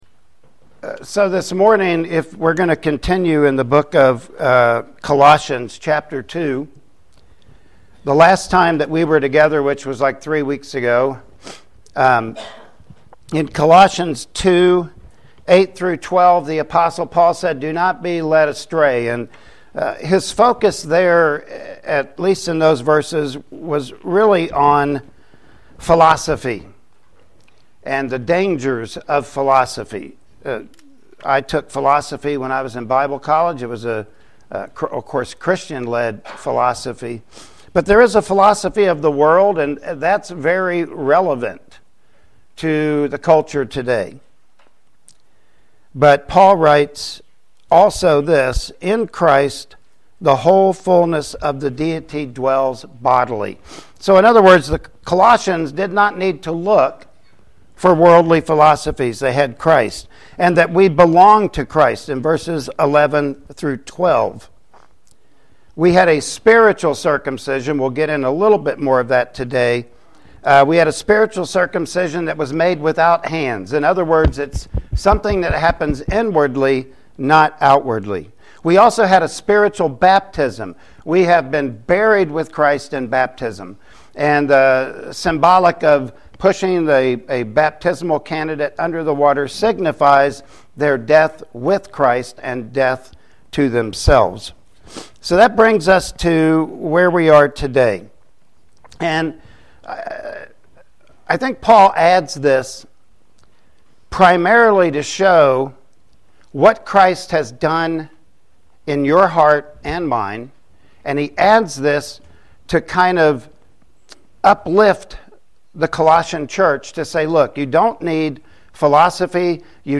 Colossians Chapter 2 Passage: Colossians 2:13-15 Service Type: Sunday Morning Worship Service Topics